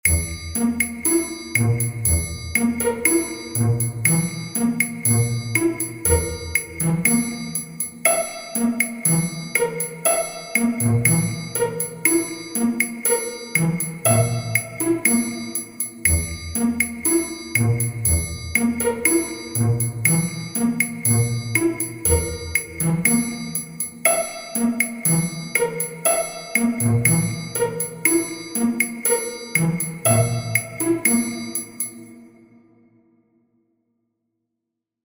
Groove in 2edo
2edo_groove.mp3